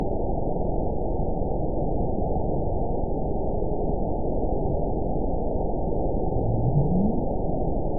event 921974 date 12/24/24 time 00:14:11 GMT (4 months, 2 weeks ago) score 9.51 location TSS-AB02 detected by nrw target species NRW annotations +NRW Spectrogram: Frequency (kHz) vs. Time (s) audio not available .wav